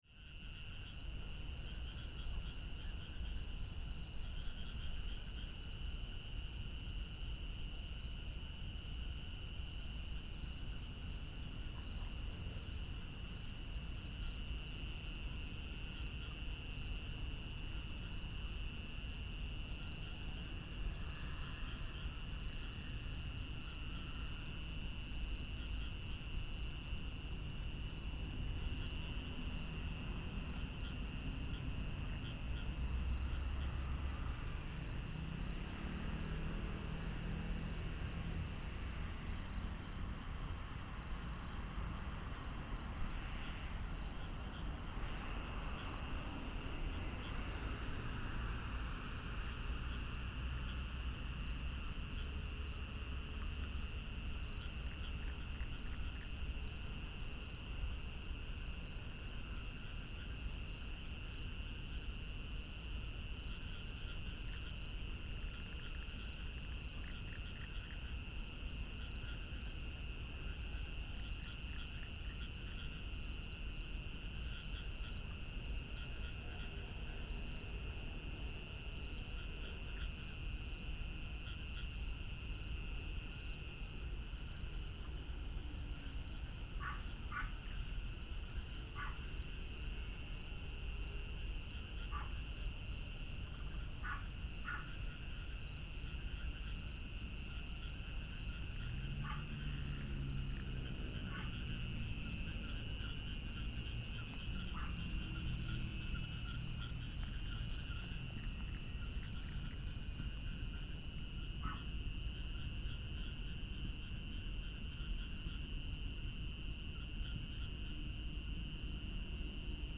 Jiande Rd., Bade Dist. - Insects and frogs